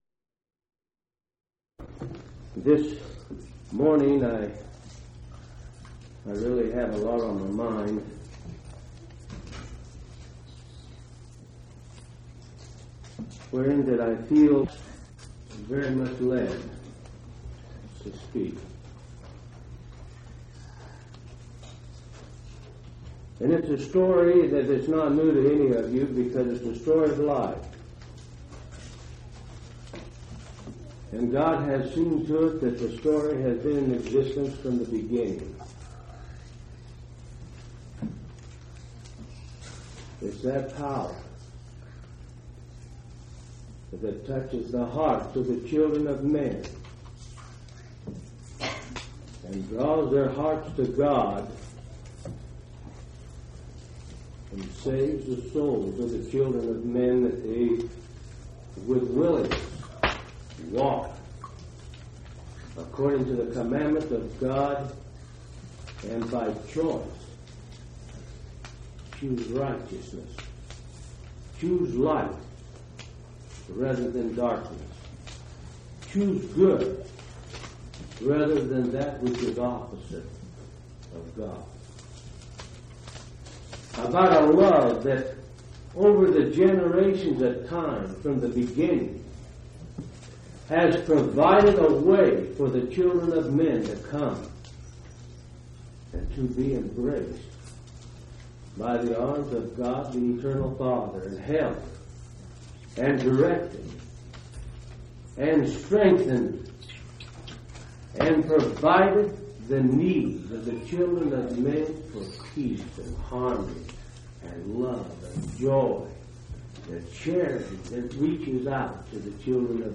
10/26/1980 Location: Phoenix Local Event